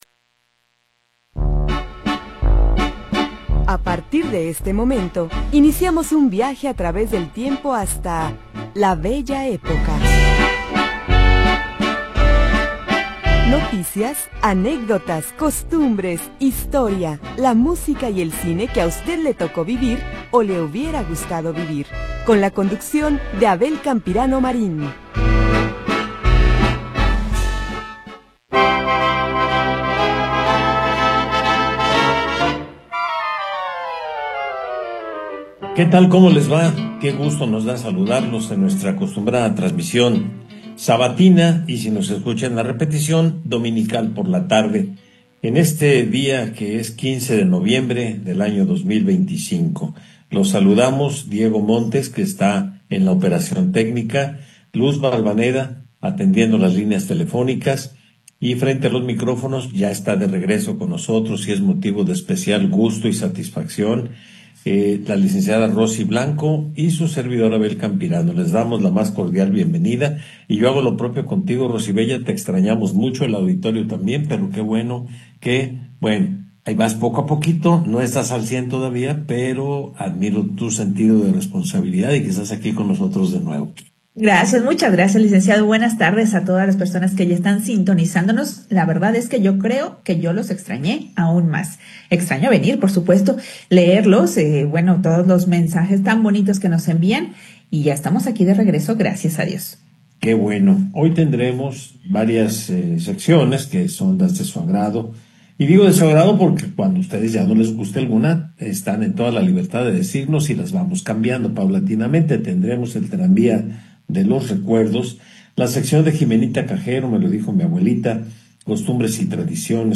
Programa transmitido el 15 de Noviembre de 2025.